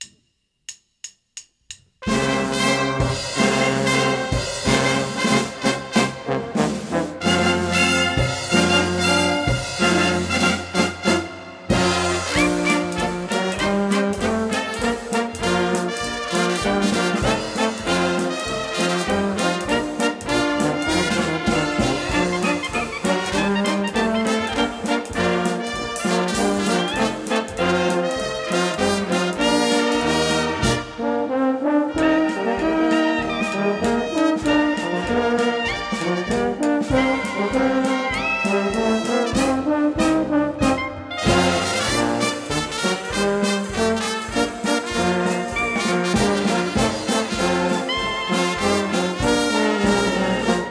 Live-Mitschnitt 3 (unbearbeitet)